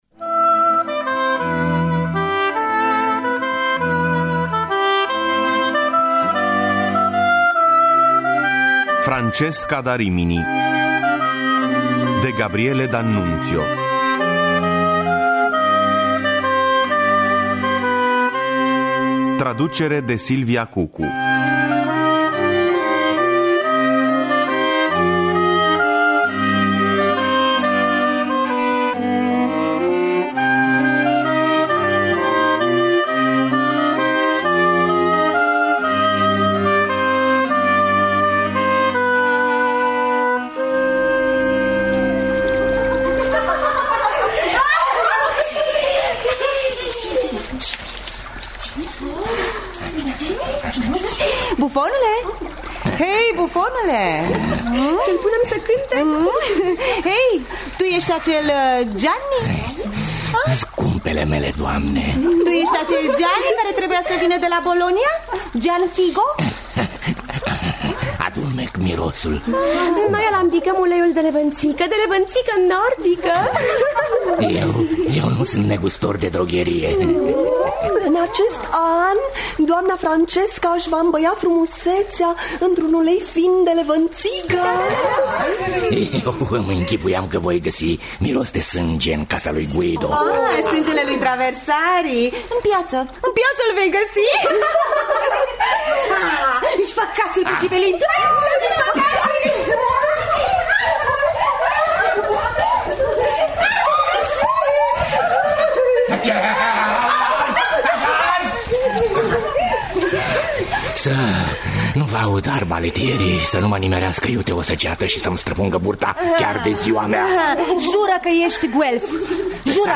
flaut
oboi
violoncel
harpă
chitară
percuţie). Înregistrări muzicale şi efecte speciale